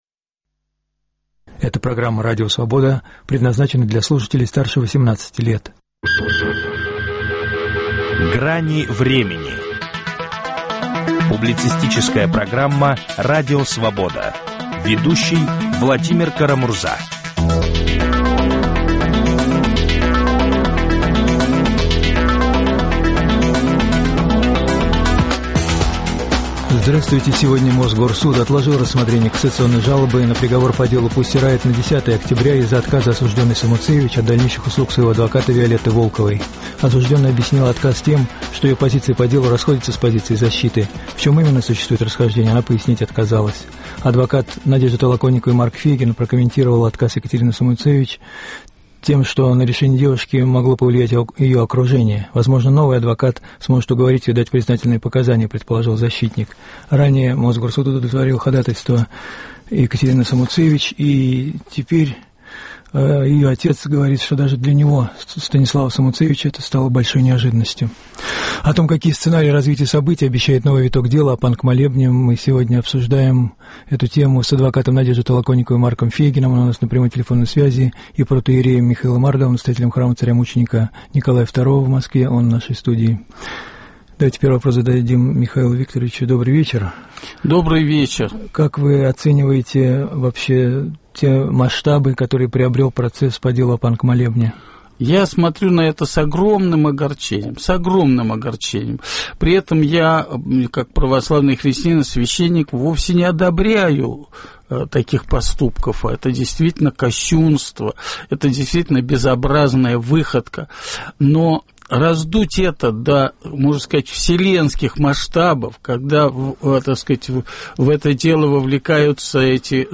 Какие сценарии развития событий обещает новый виток дела о панк-молебне? Об этом беседуют адвокат Надежды Толоконниковой Марк Фейгин и протоиерей Михаил Ардов.